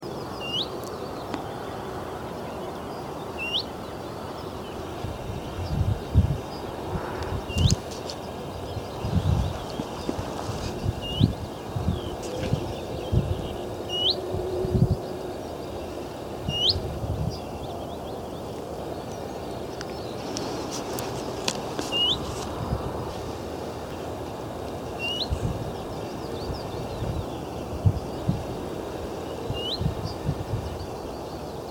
Pouillot fitis
Son chant bien que répétitif est mélodieux.
Cris du Pouillot fitis sautant de branches en branches, 18 avril 2023